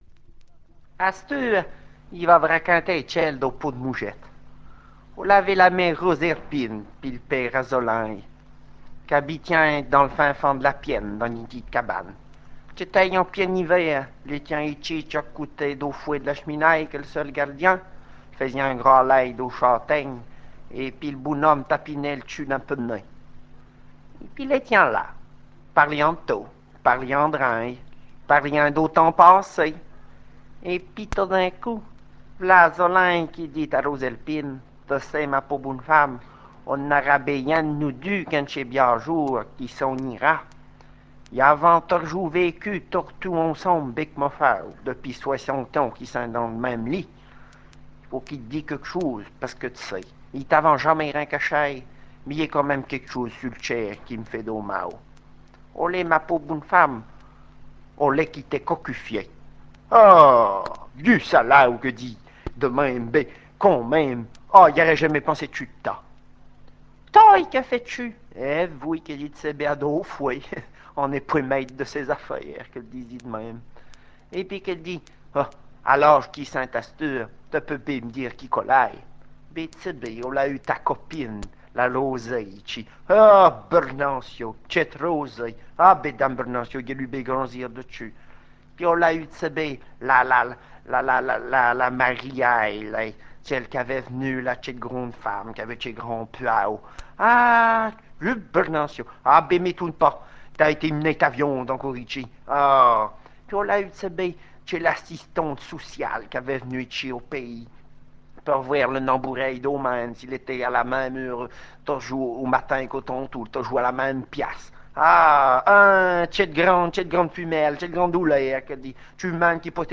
Le pot d'mougettes (histoire)